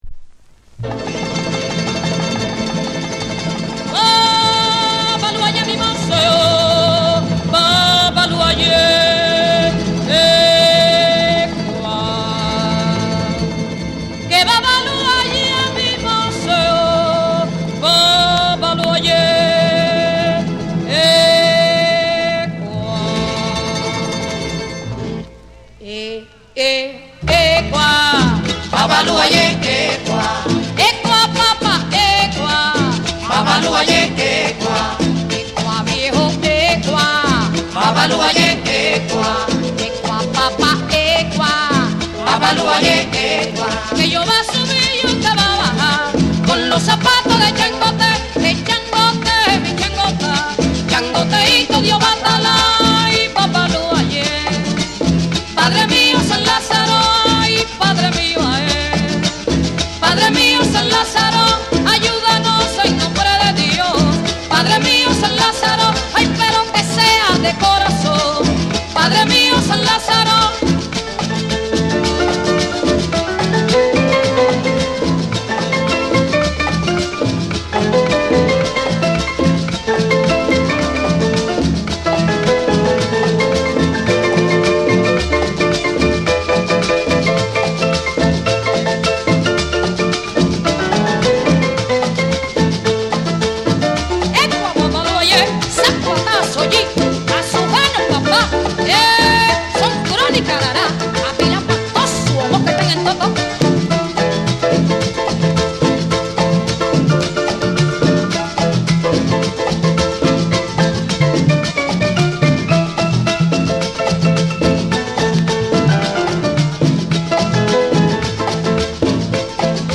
キューバ出身の夫婦デュオ
伝統的なソンやグアラーチャ、グアヒーラを中心に、温かみのあるギターとリズミカルなパーカッション
WORLD